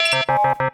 UIBeep_Access Denied Negative.wav